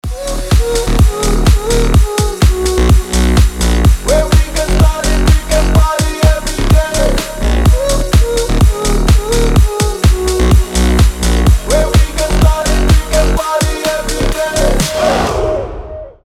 • Качество: 320, Stereo
громкие
басы
энергичные
Песня болельщиков